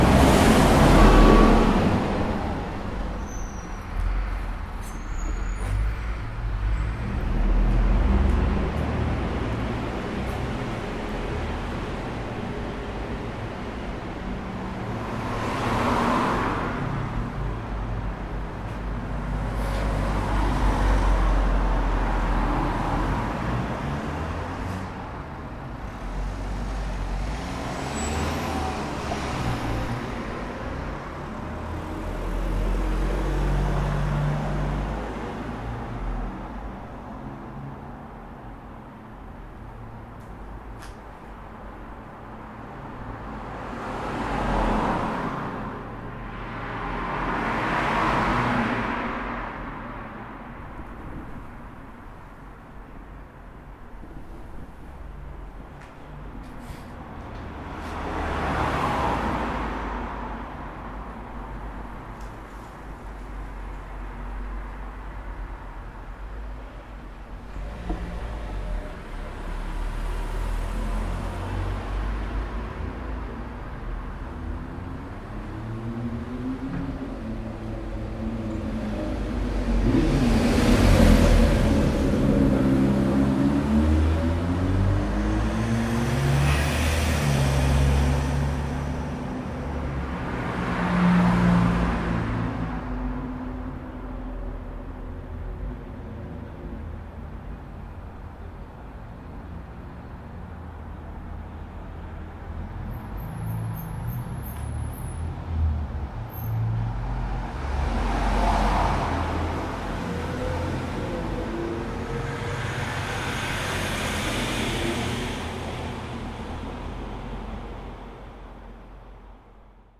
Inside a reverby garage, Bath
The sound from inside a reverby garage on Lansdowne Road, Bath.